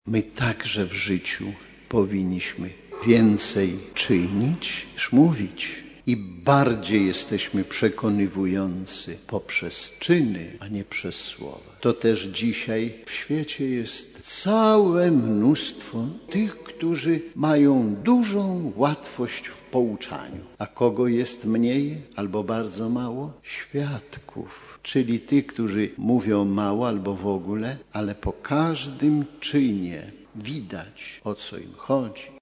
W czasie mszy św. biskup diecezji warszawsko-praskiej podkreślił, że obowiązkiem człowieka wiary jest dawanie świadectwa o Chrystusie swoim życiem.